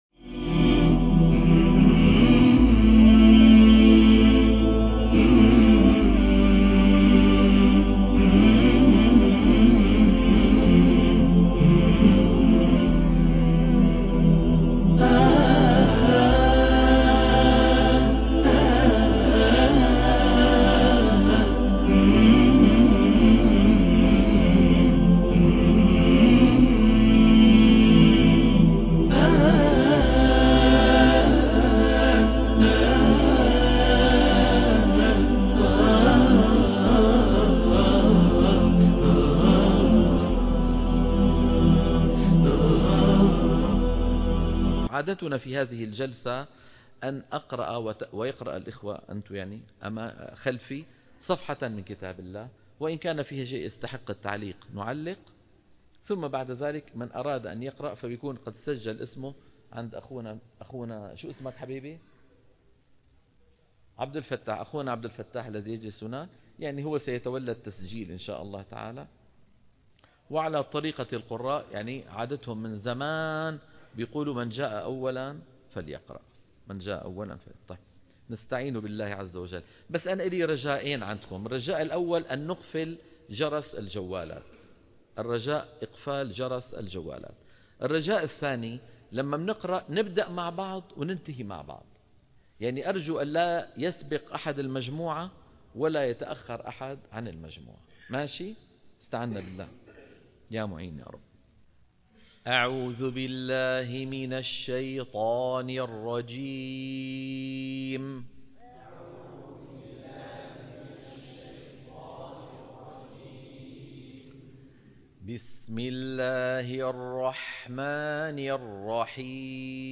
تصحيح التلاوة الحلقة - 43 - تلقين الصفحة 151